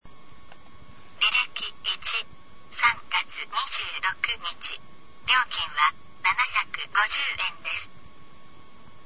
DIU-9000 音声案内　（MP3録音ファイル）